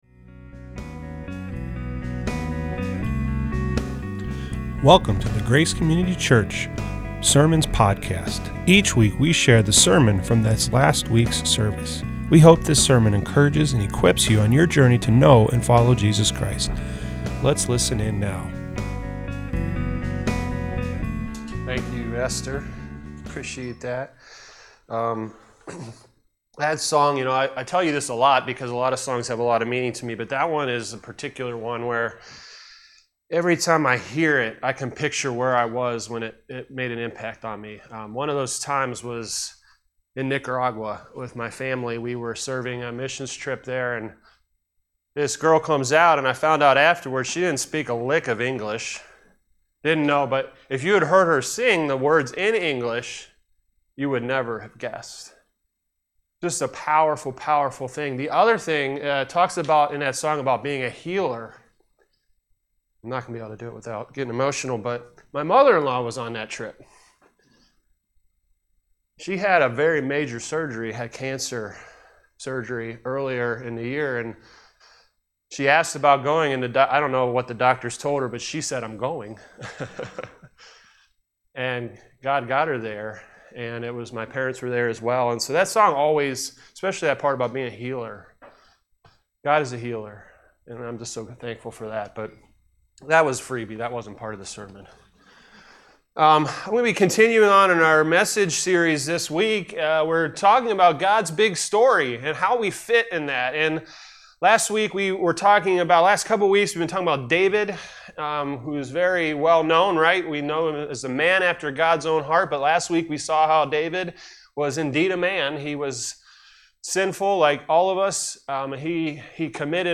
This week we continued our new series titled ‘God’s big story, and how YOU fit!’ The title of this message is “The king who had it all” Primary scripture passages come from 1 Kings and Proverbs.